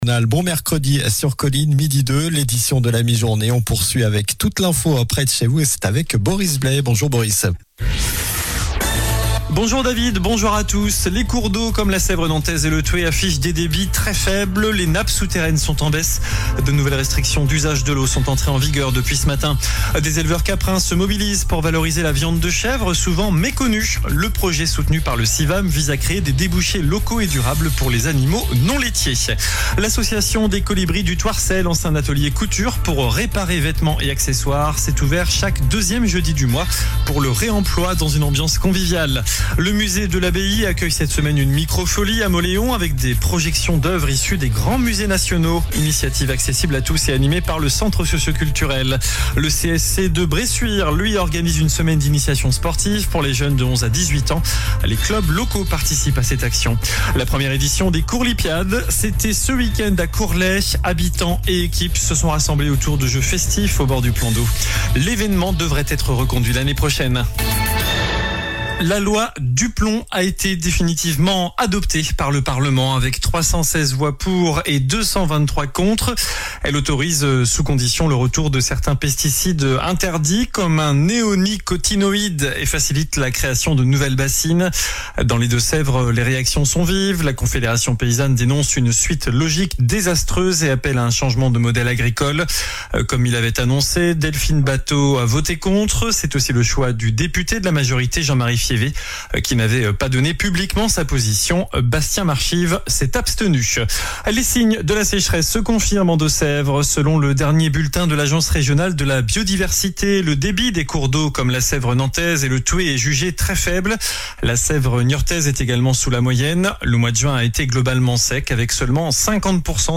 Journal du mercredi 9 juillet (midi)